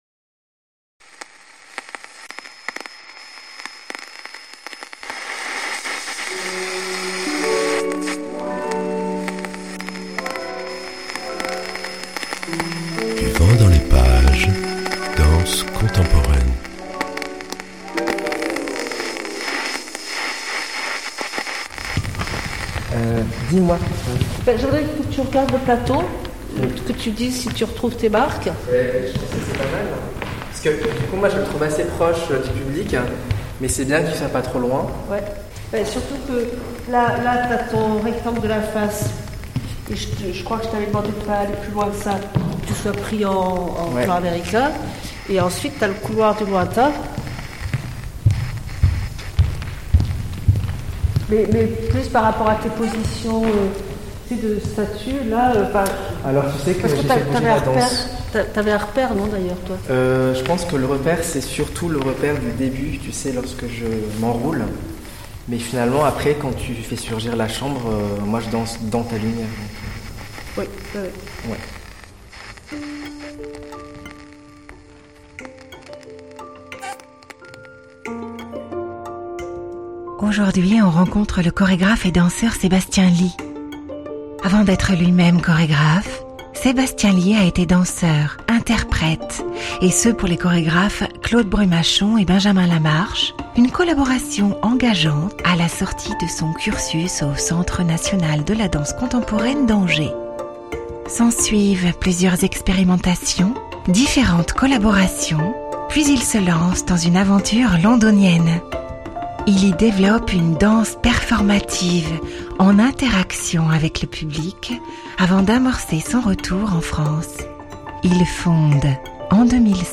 Du Vent dans les Pages, magazine sonore, vous entraîne chaque mois à la rencontre d’un Artiste autour de Marseille.